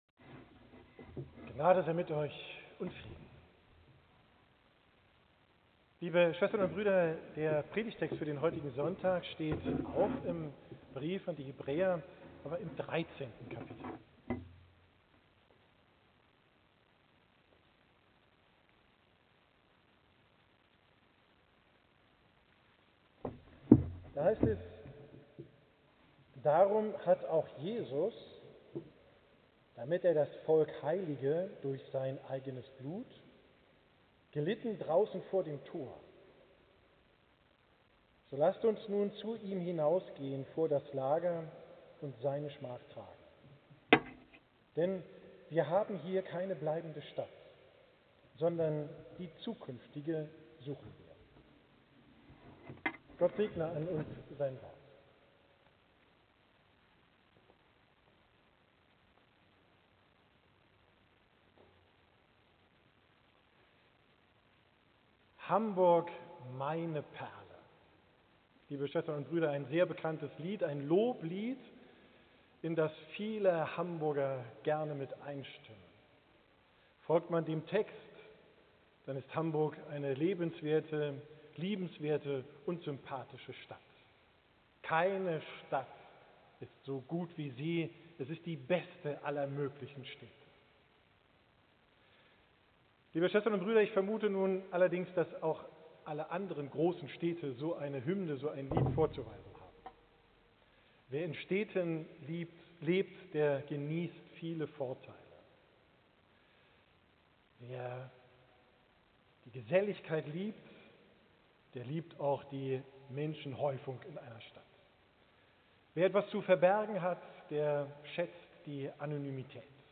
Predigt vom Sonntag Judika, 22.